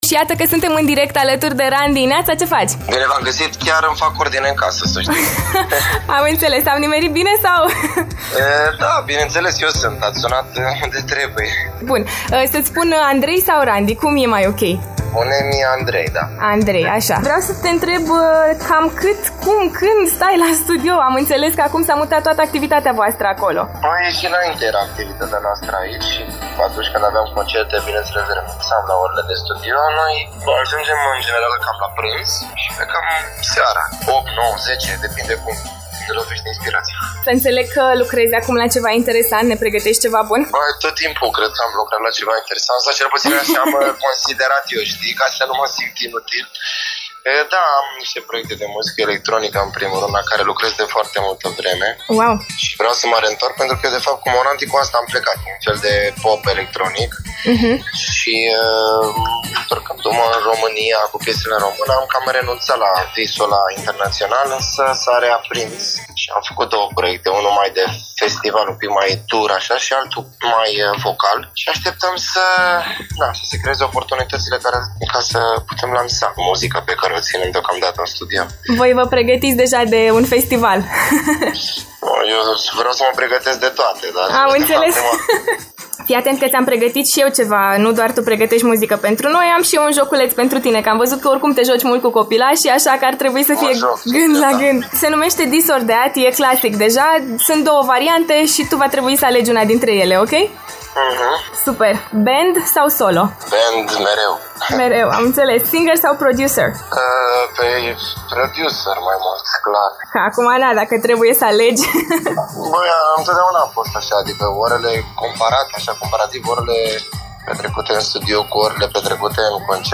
RANDI ÎȘI ALEGE FAVORIȚII, LIVE LA AFTER MORNING